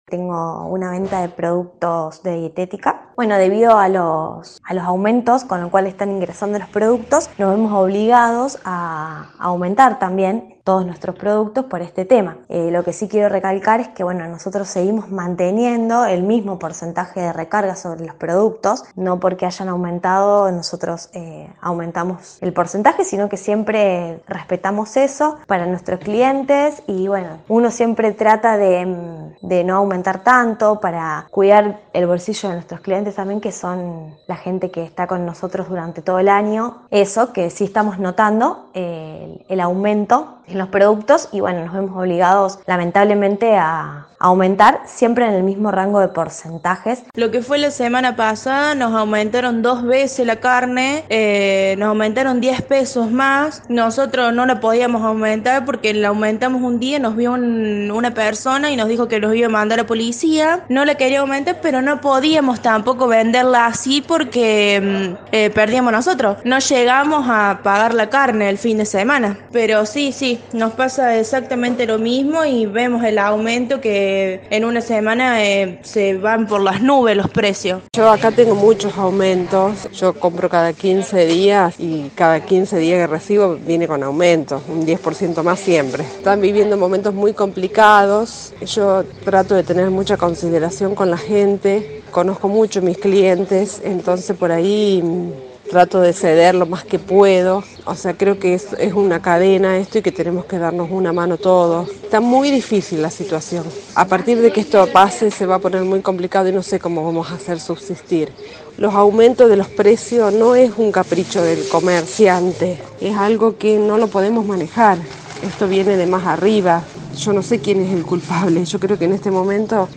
Escucha los testimonios.
Comerciantes del rubro alimentos que fueron consultados por La Urbana respecto del movimiento de precios en diferentes productos, manifestaron que desde hace algunas semanas vienen recibiendo la mercadería con distintos porcentajes de incremento.